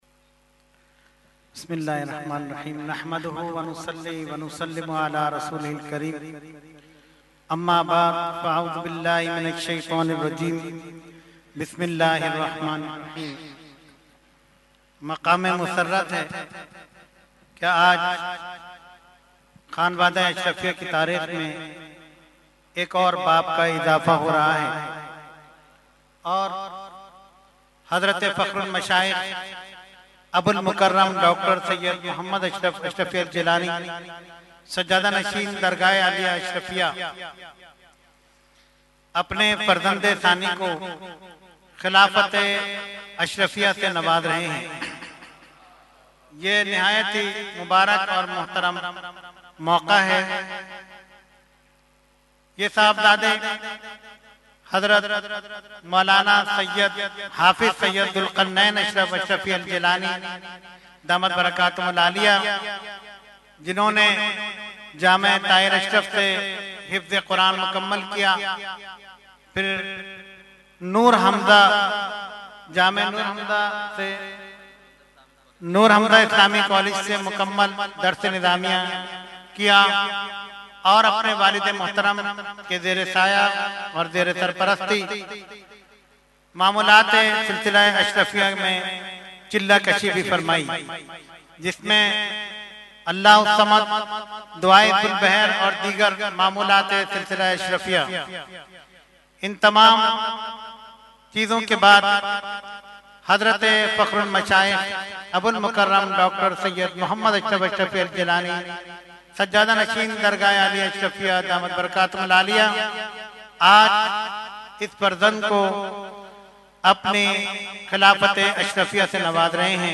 held on 11,12,13 December 2022 at Dargah Alia Ashrafia Ashrafabad Firdous Colony Gulbahar Karachi.